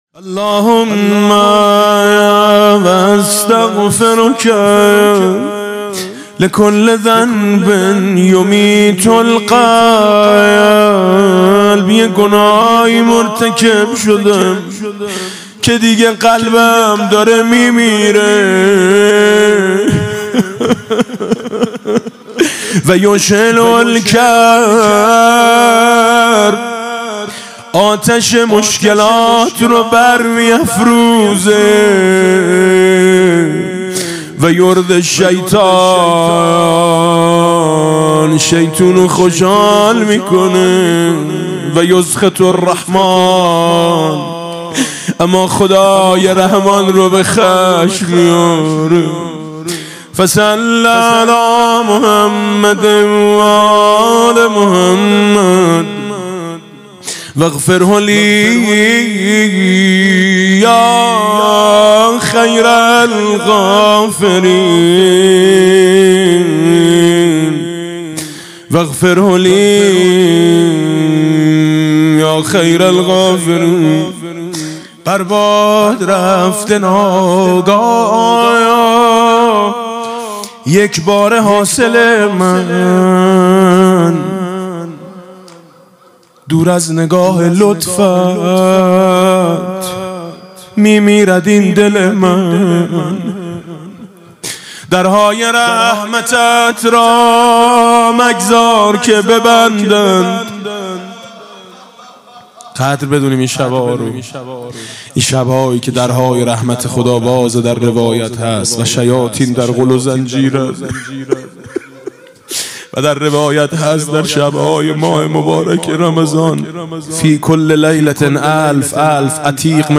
قرائت استغفار ۷۰ بندی حضرت امام علی علیه السلام با نوای دلنشین حاج میثم مطیعی